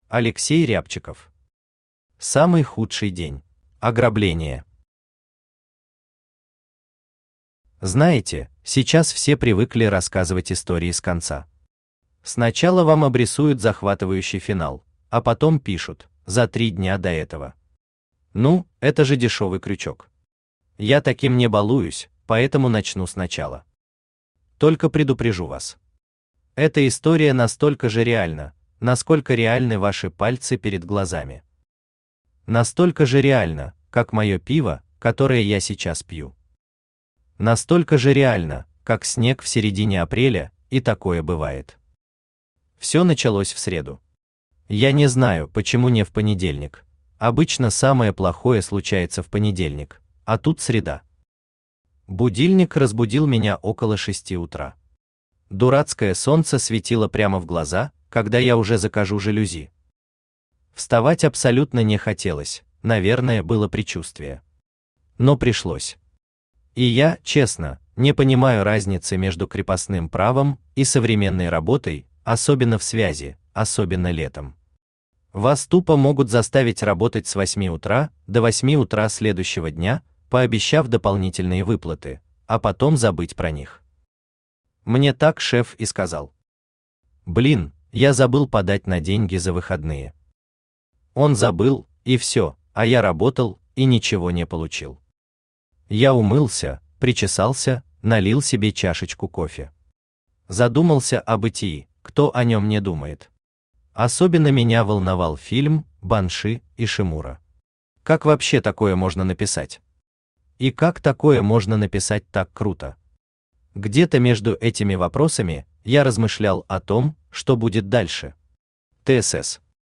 Аудиокнига Самый худший день | Библиотека аудиокниг
Aудиокнига Самый худший день Автор Алексей Рябчиков Читает аудиокнигу Авточтец ЛитРес.